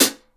Closed Hats
West MetroHihat (4).wav